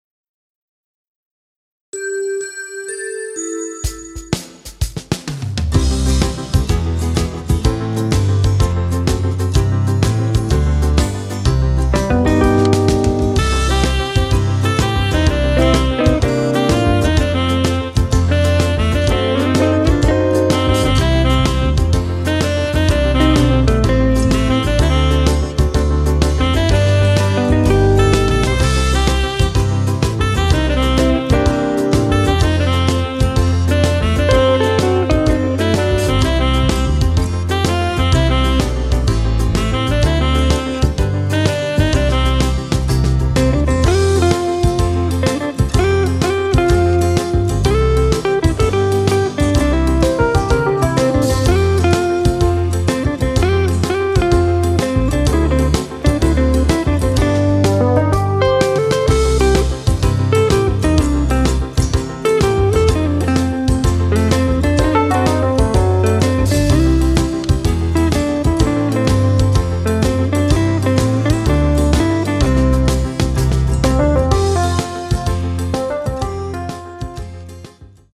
Square Dance Music